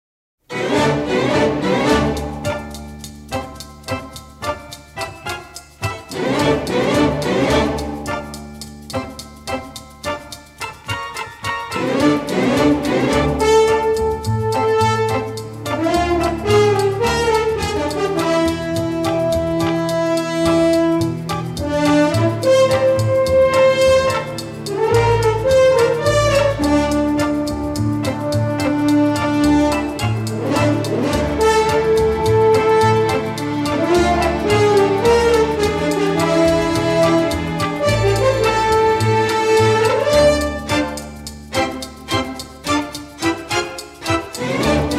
and a creepy score including harpsichord and solo soprano.